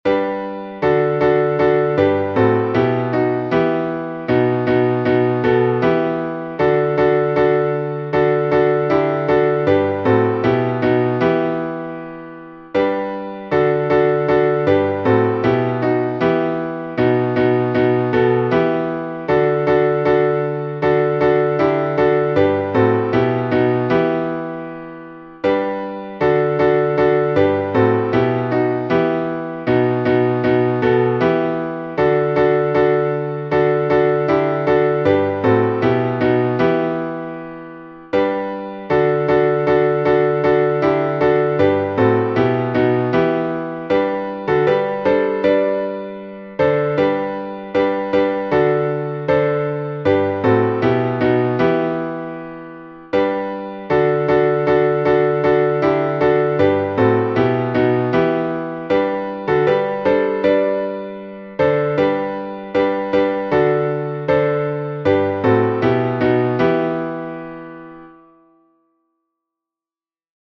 Валаамский напев